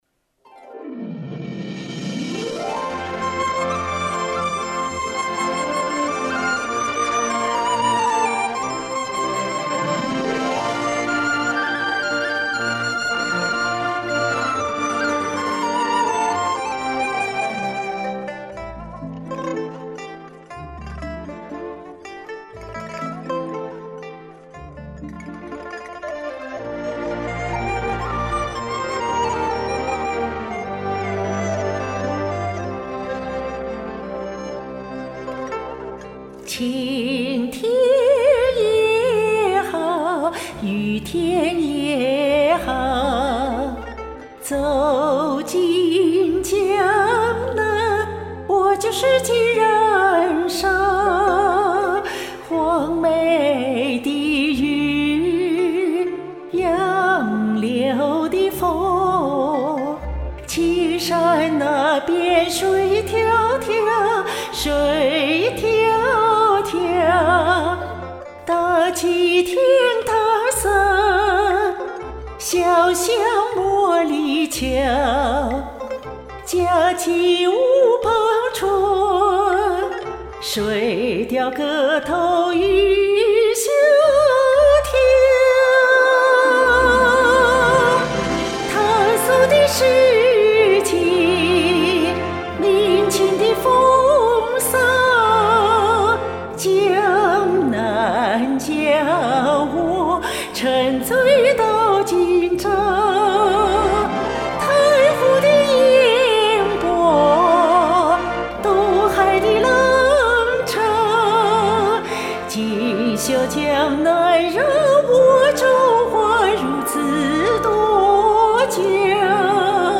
不过, 绿营也需要飒爽英姿的女兵不是?